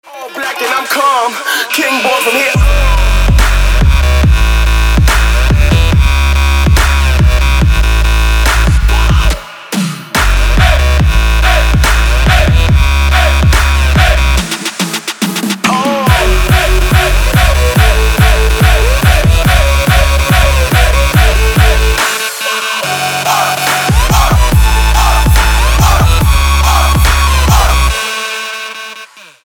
• Качество: 256, Stereo
Trap
Bass
Tribal Trap
drop